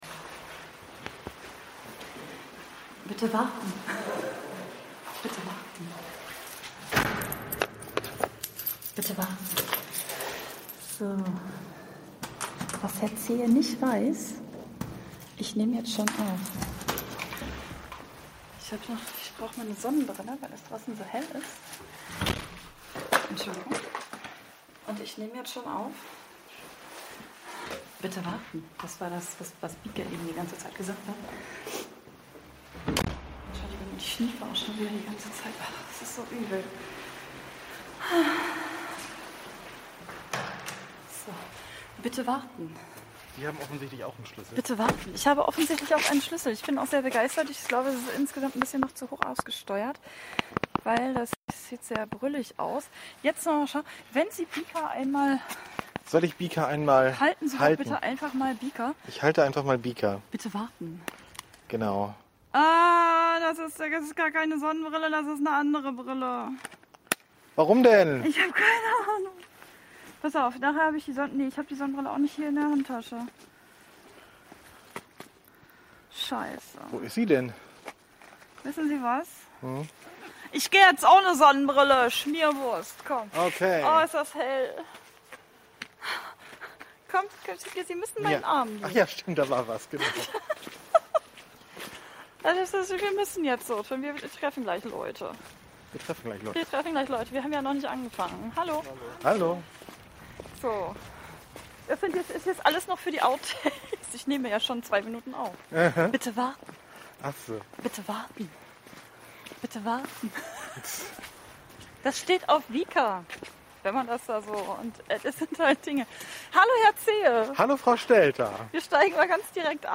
Wir wandern mal wieder draußen herum und reden über Dinge. Diesmal enthalten: die ultimative Musikempfehlung zum Mitsingen (wer sich traut), Ohrwurm-Ping-Pong und die Auslosung der Gewinnspiel-Gewinnerin.